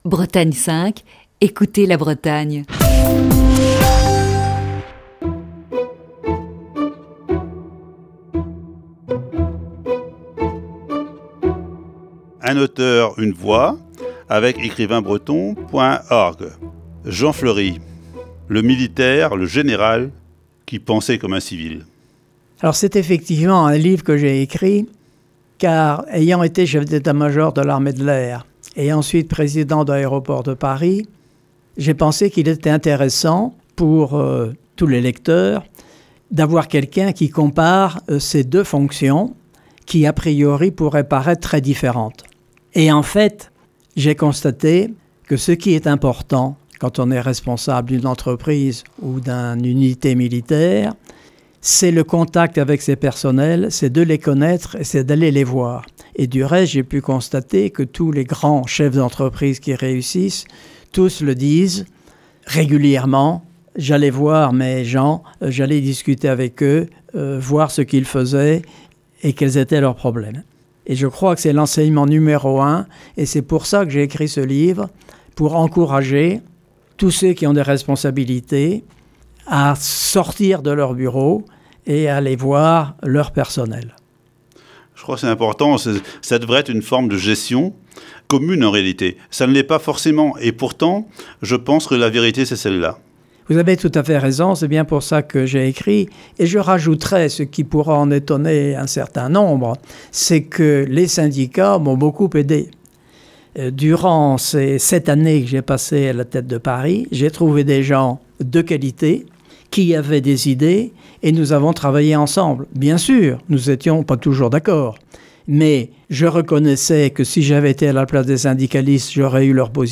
Émission du 1er janvier 2021. Un Auteur, Une Voix fait relâche en cette période de vacances de fin d'année.